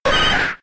Cri de Sabelette K.O. dans Pokémon X et Y.